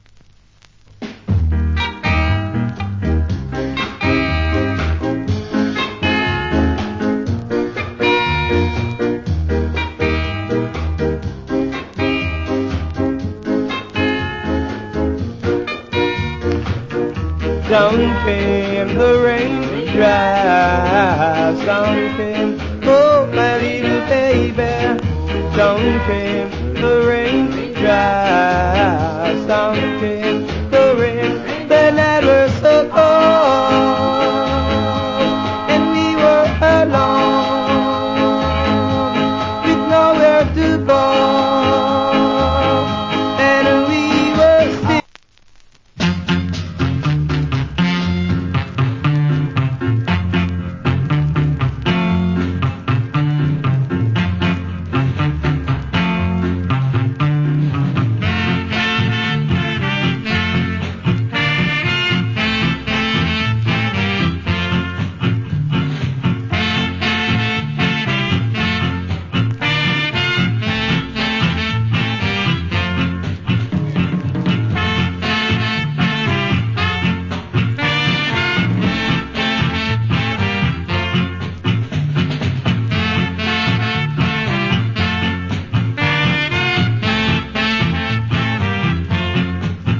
Wicked Ska Vocal.